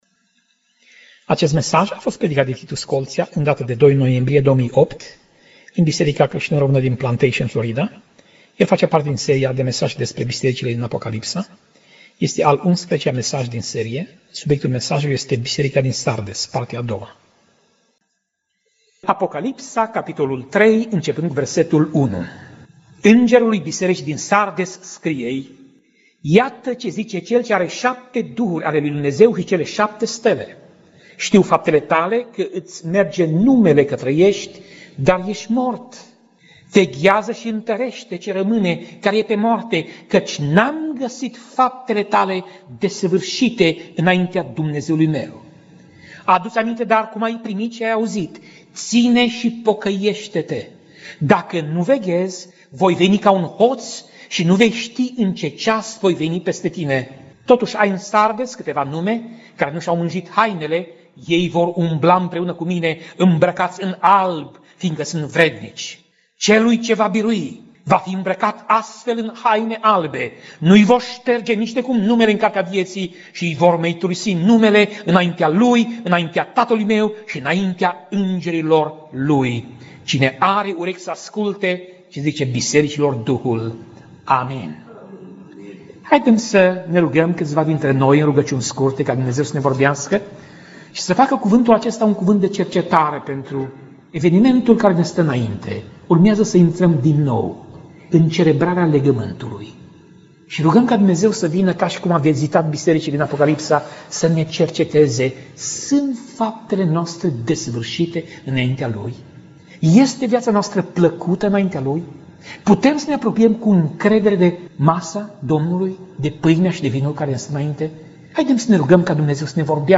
Pasaj Biblie: Apocalipsa 3:1 - Apocalipsa 3:6 Tip Mesaj: Predica